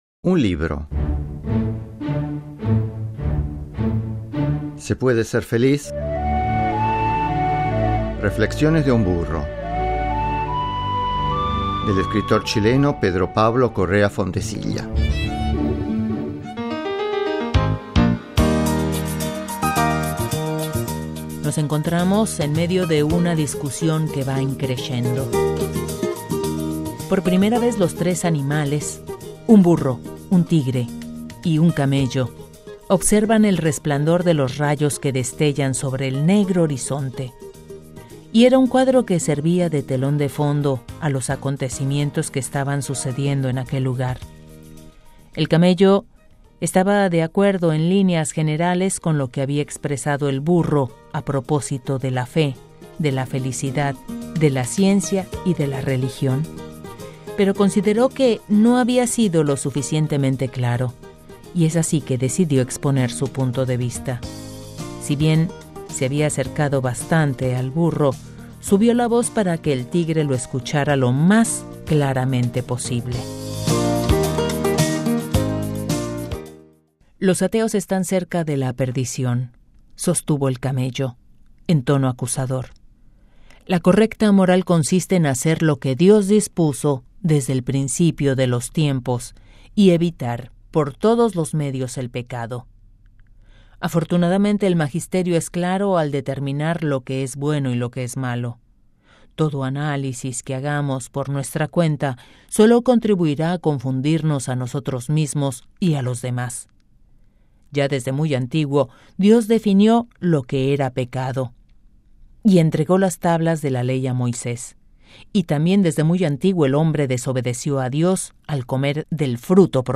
Adaptación Radiofónica de un libro: